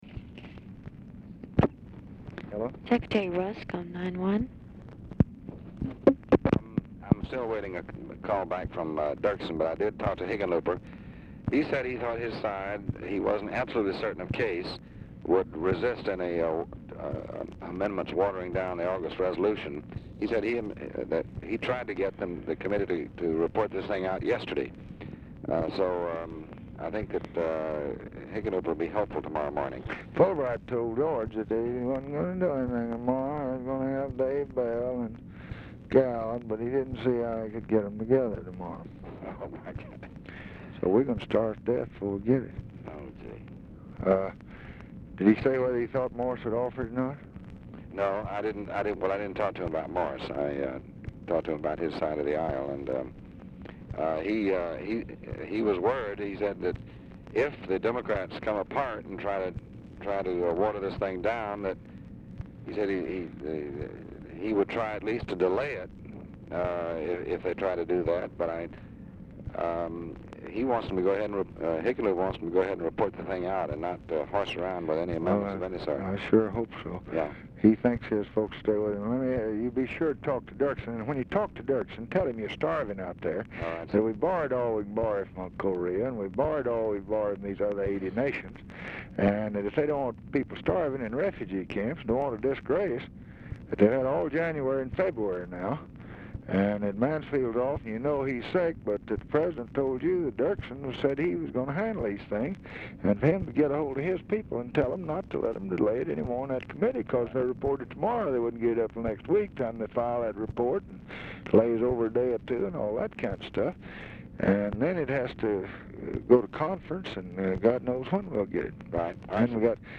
Telephone conversation # 9665, sound recording, LBJ and DEAN RUSK, 2/22/1966, 6:50PM | Discover LBJ
Format Dictation belt
Location Of Speaker 1 Oval Office or unknown location
Specific Item Type Telephone conversation